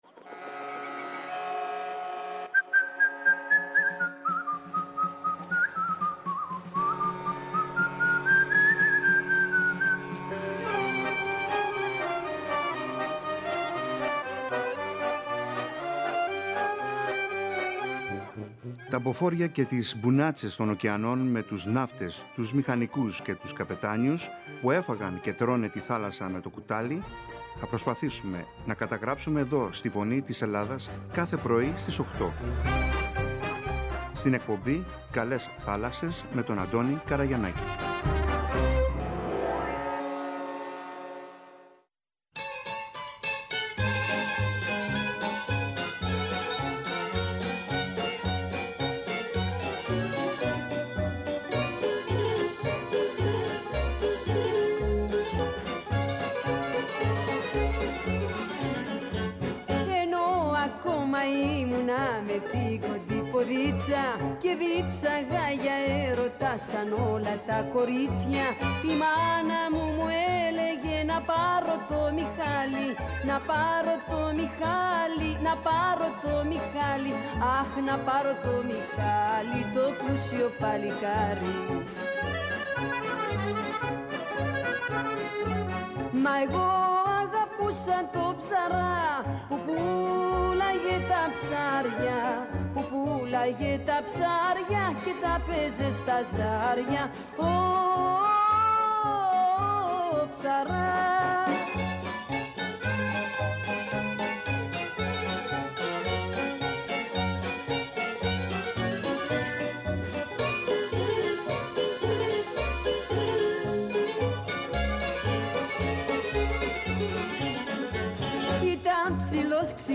Ο Γενικός Γραμματέας του Υπουργείου Εμπορικής Ναυτιλίας και Νησιωτικής Πολιτικής κ. Μανώλης Κουτουλάκης μίλησε για την σύμβαση προμήθειας έντεκα (11) σκαφών κατάλληλα διαμορφωμένων με υγειονομικό εξοπλισμό, μήκους άνω των 11μ., που υπεγράφη την προηγούμενη Παρασκευή (7/6) από το Υπουργείο Ναυτιλίας και Νησιωτικής Πολιτικής και την Viking Norsafe Life-Saving Equipment Hellas.